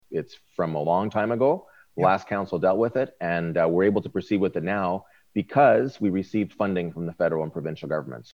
Mayor Mitch Panciuk commented.